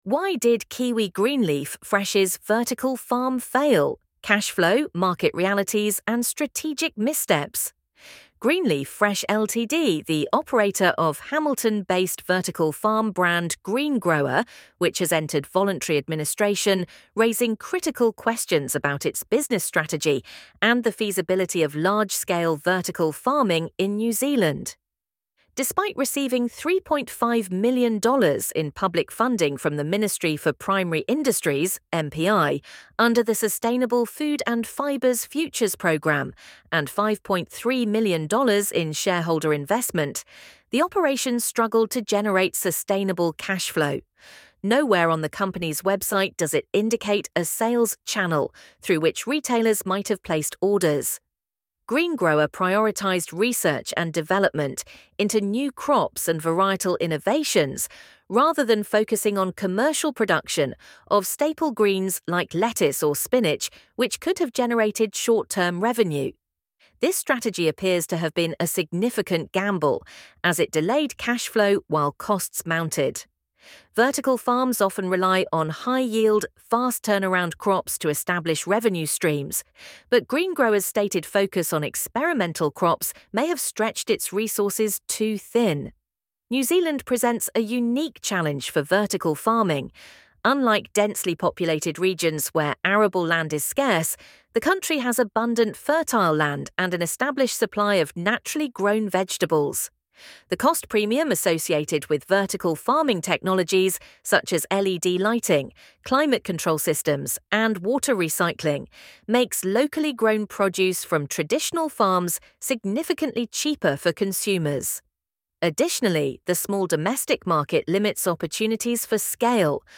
LISTEN TO THIS NEWS ARTICLE HERE 3min:51sec